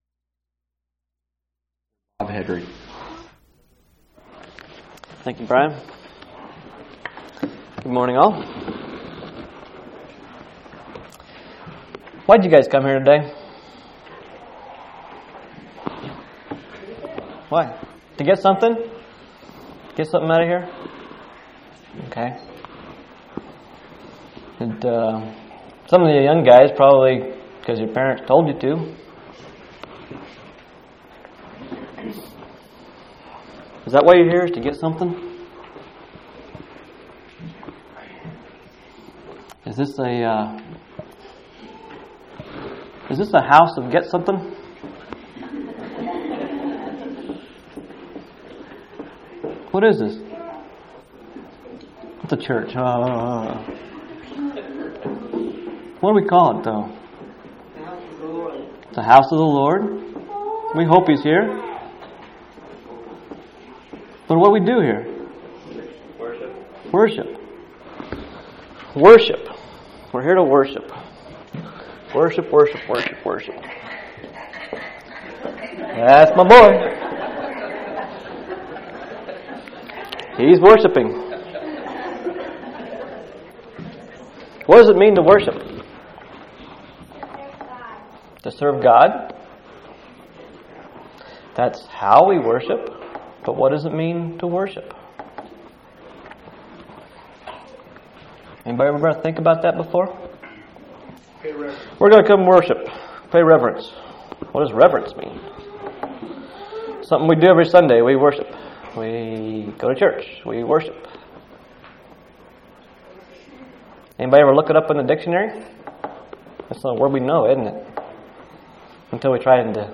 Worship – Resources Home